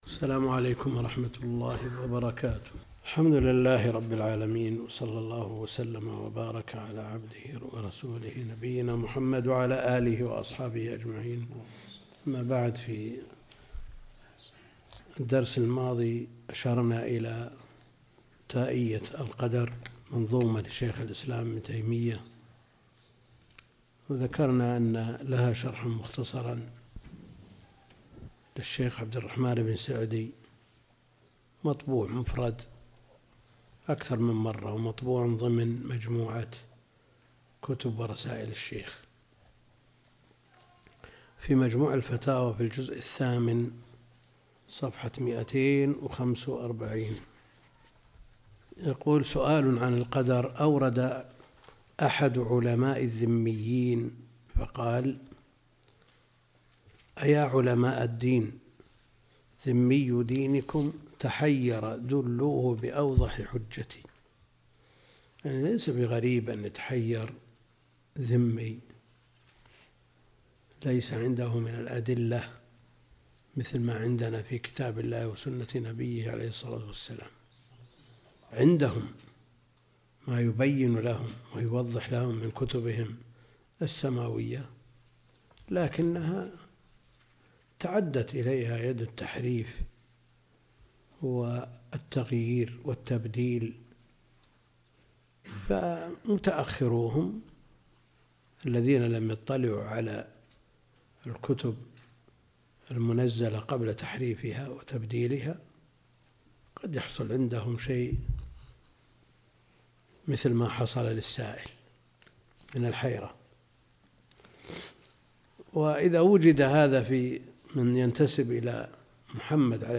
الدرس (66) شرح العقيدة الطحاوية - الدكتور عبد الكريم الخضير